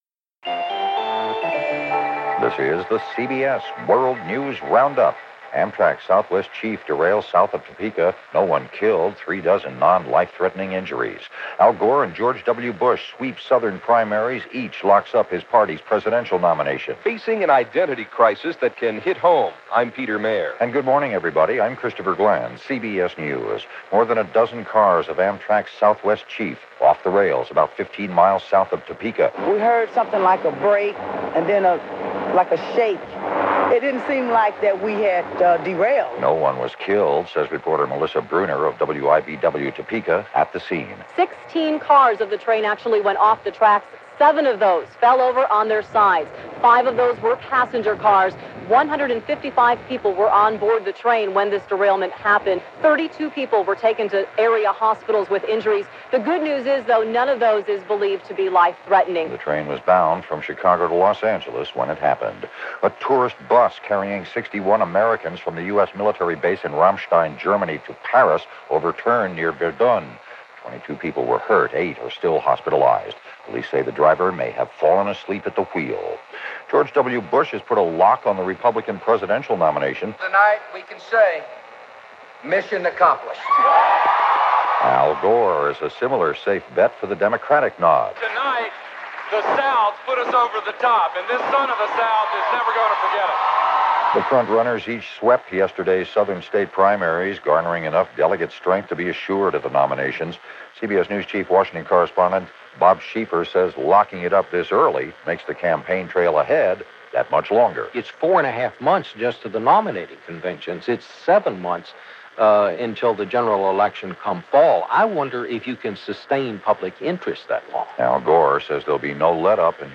And that’s a tiny fraction of what happened, this March 15, 2000 as reported by The CBS World News Roundup.